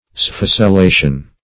Meaning of sphacelation. sphacelation synonyms, pronunciation, spelling and more from Free Dictionary.
Search Result for " sphacelation" : The Collaborative International Dictionary of English v.0.48: Sphacelation \Sphac`e*la"tion\, n. (Med.) The process of becoming or making gangrenous; mortification.